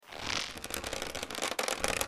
skyrim_bow_pull.mp3